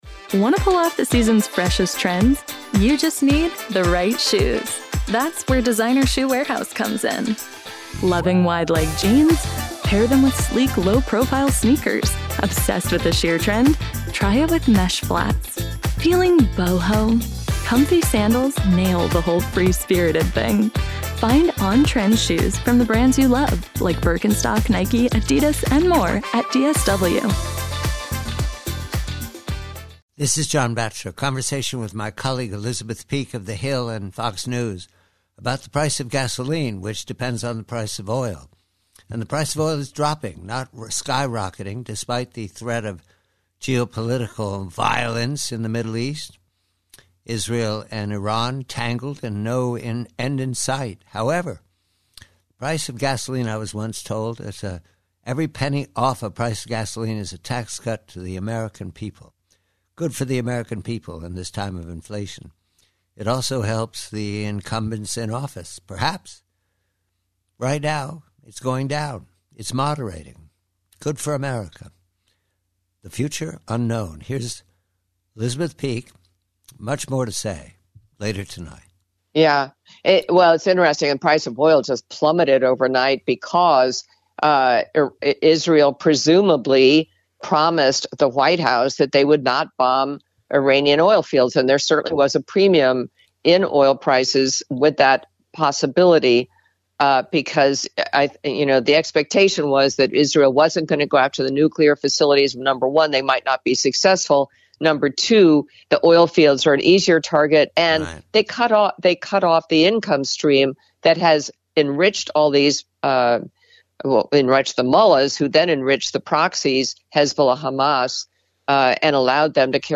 Preview: Oil: Conversation with colleague Elizabeth Peek of Fox News regarding the moderating price of oil and the boost this gives to the incumbents in an election cycle.